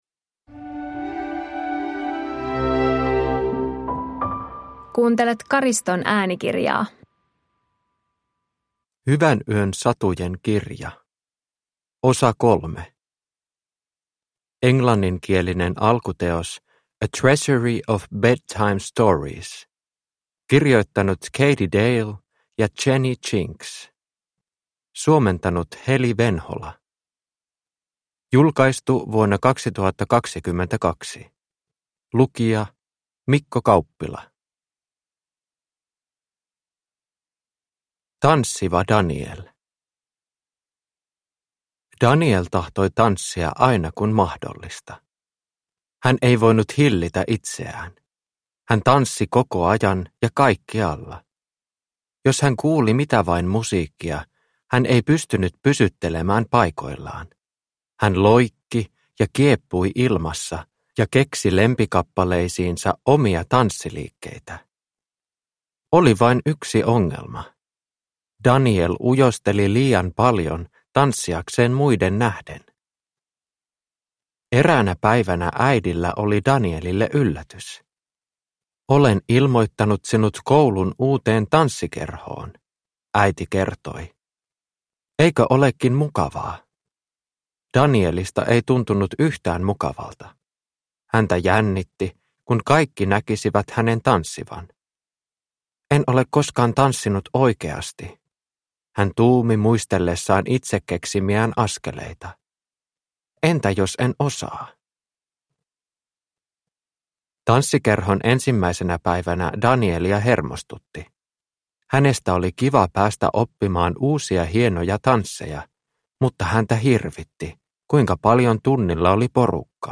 Hyvänyön satujen kirja 3 – Ljudbok – Laddas ner